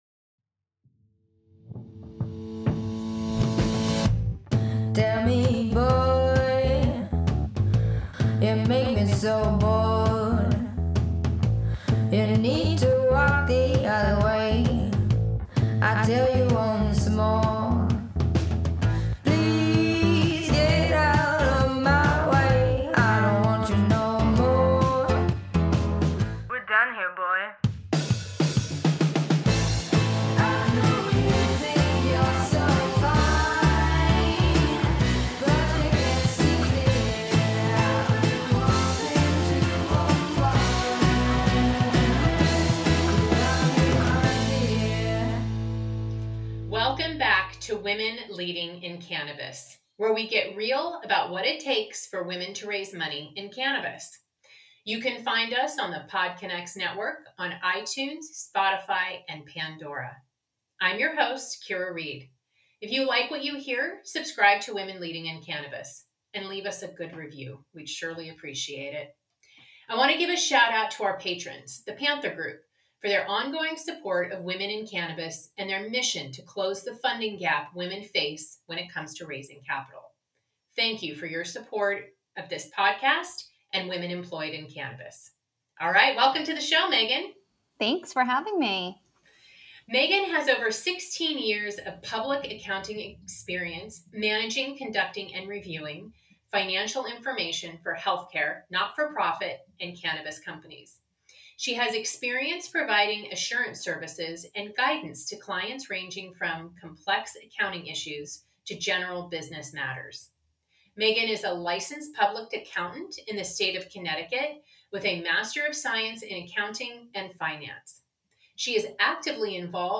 Raw, inspiring and real conversations with women leading the worldwide cannabis industry. Learn what it takes to create a successful business, change policy, fight stigma and thrive as a woman in a male dominated environment.